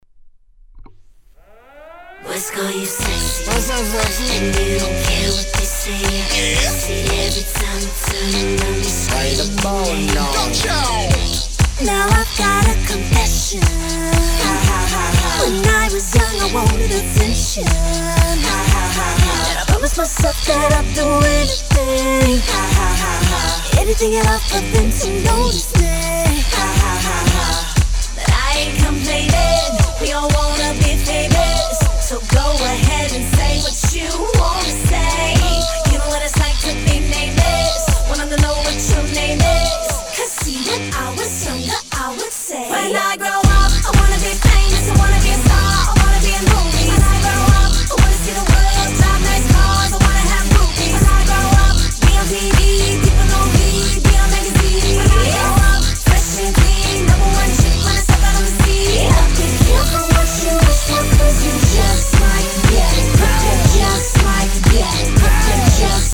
HIP HOP/R&B
疾走するBEATにハードなシンセ、セクシーで格好いい彼女等の姿が垣間見れるフロアチューン！！